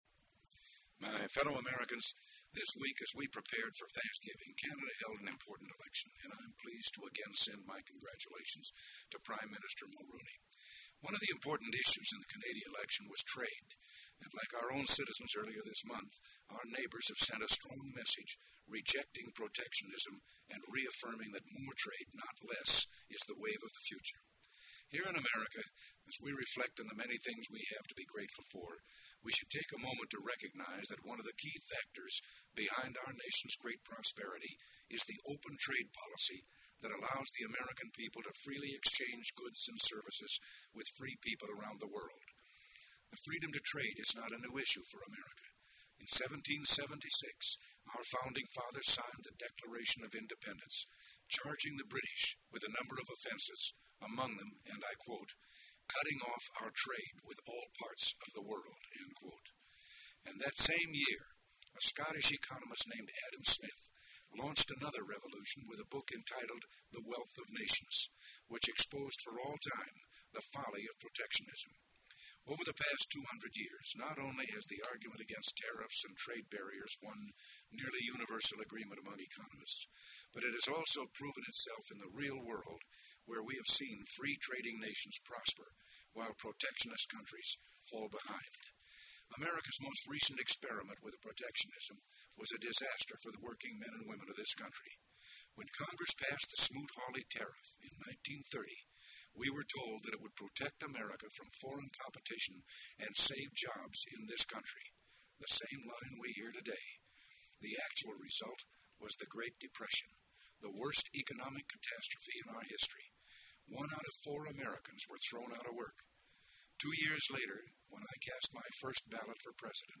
Post Thanksgiving Day Radio Address on Free Trade
delivered 26 November 1988, Rancho del Cielo, Santa Barbara County, CA
Audio Note: AR-XE = American Rhetoric Extreme Enhancement
ronaldreagan1988radioaddressfreetrade.mp3